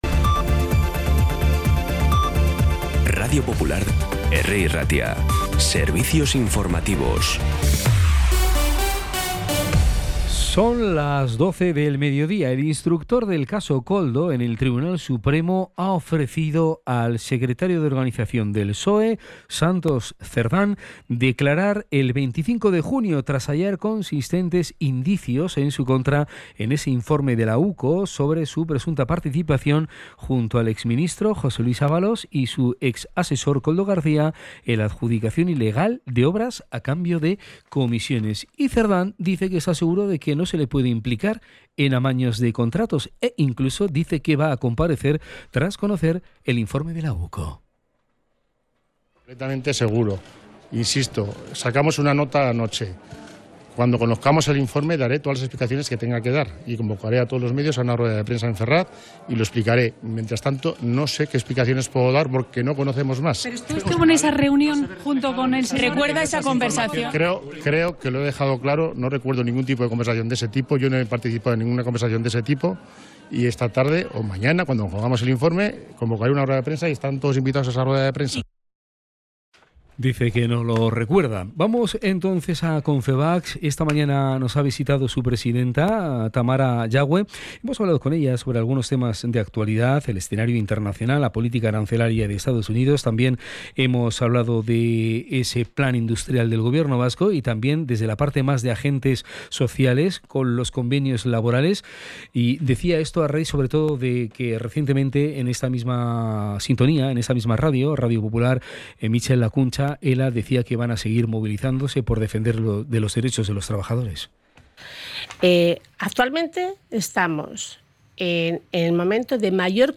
Las noticias de Bilbao y Bizkaia del 13 de junio a las 12
Los titulares actualizados con las voces del día. Bilbao, Bizkaia, comarcas, política, sociedad, cultura, sucesos, información de servicio público.